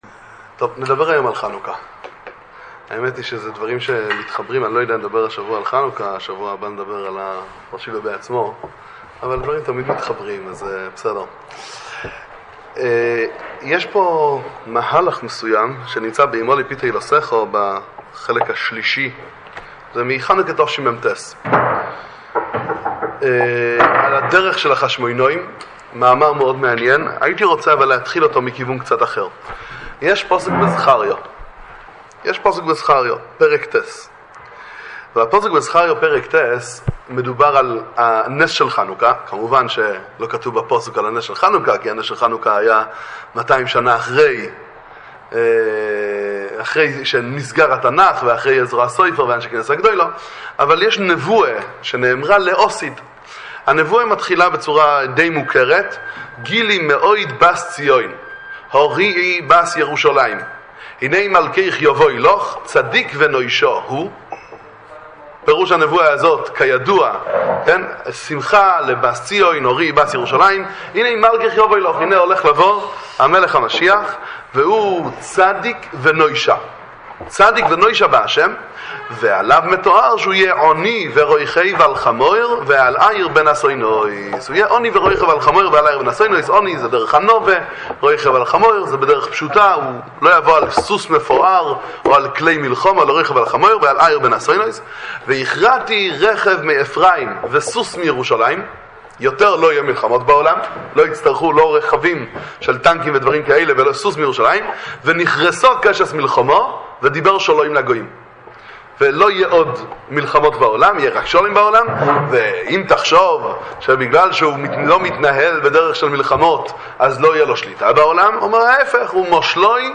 הכוח של בני חשמונאי. שיעורי תורה לחנוכה, הדלקת נרות, וחודש כסלו – לצפיה ולהאזנה